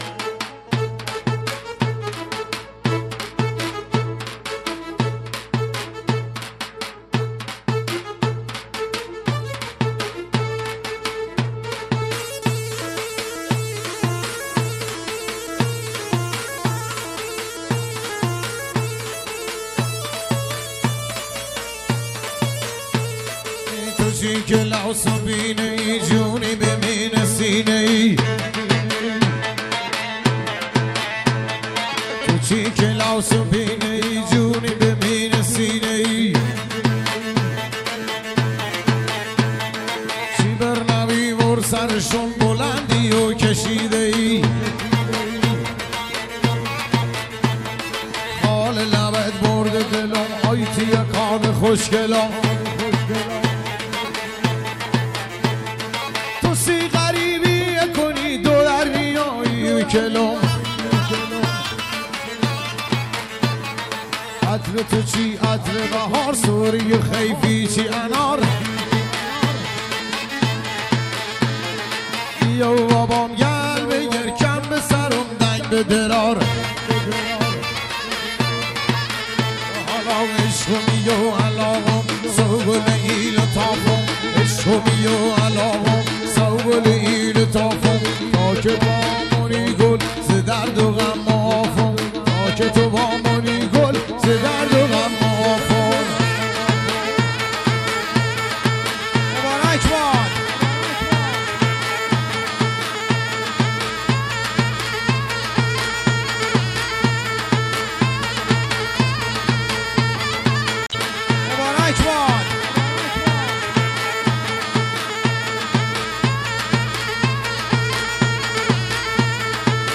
شاد لری عروسی